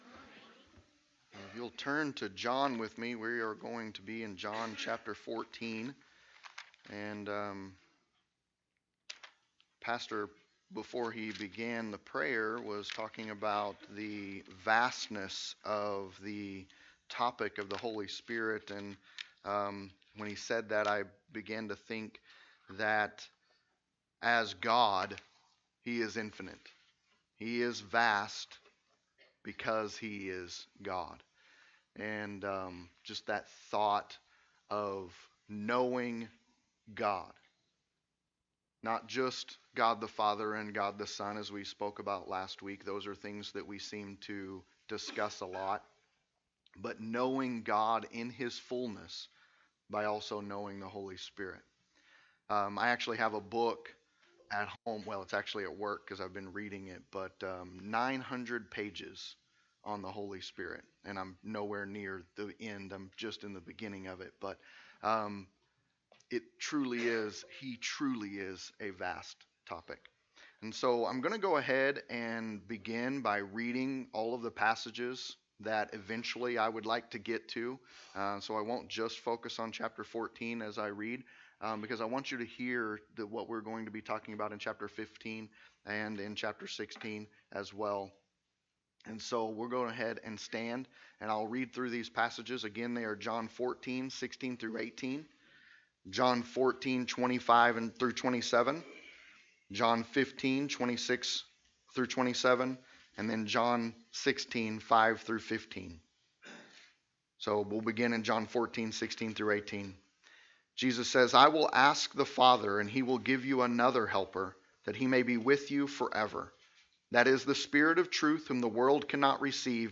Sermons preached at Hope Community Bible Church (Hope CBC) in Rogers, AR.